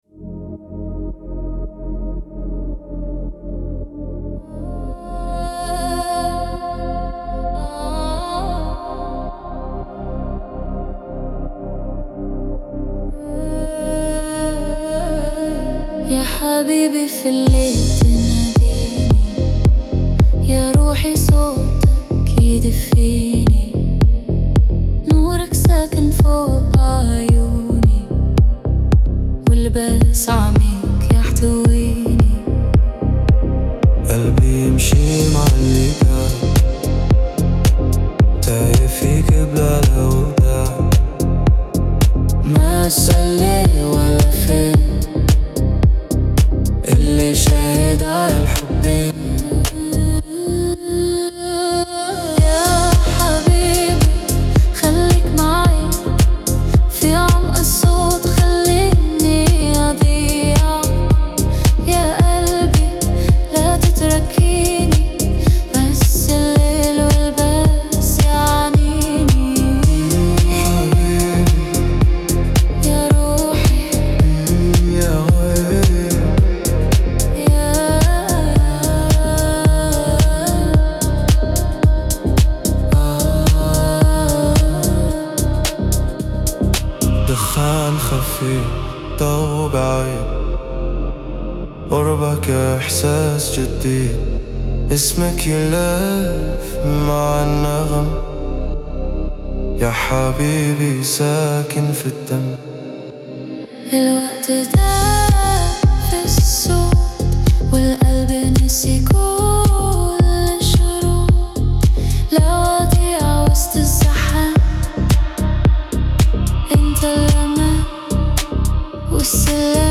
танцевальная музыка
диско
эстрада